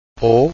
like ‘oa’ in ‘coal’
o-close.wav